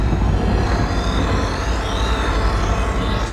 plasmaCannonHum.wav